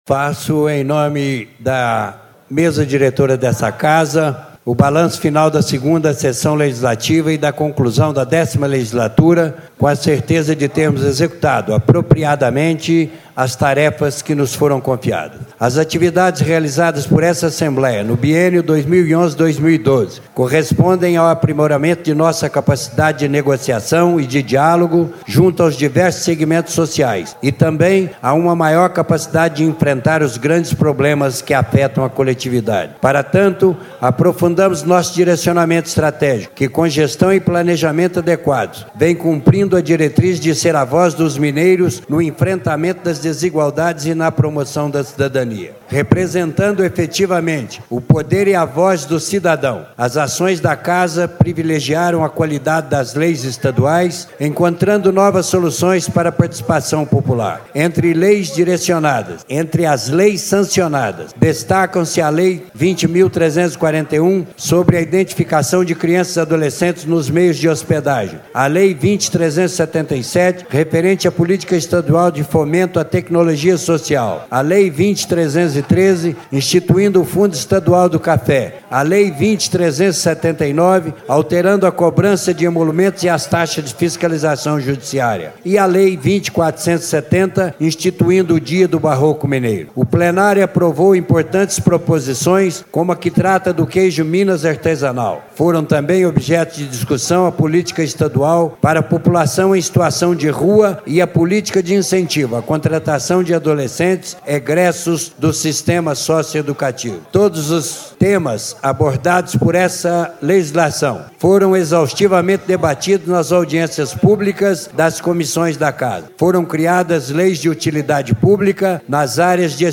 Deputado Dilzon Melo (PTB), 1º Secretário da Mesa. Balanço final em Plenário.